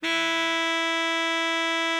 bari_sax_064.wav